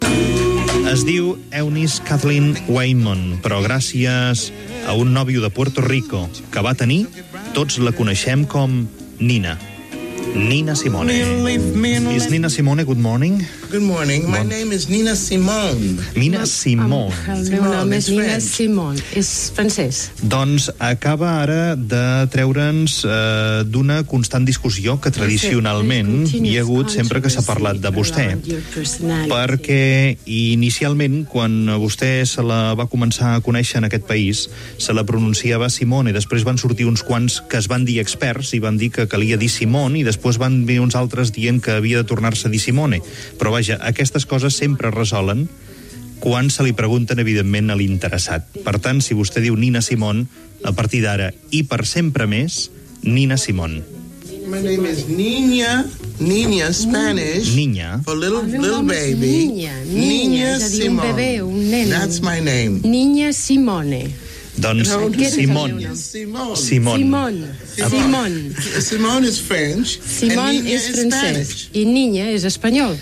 Fragment d'una entrevista a la cantant Nina Simone.
Info-entreteniment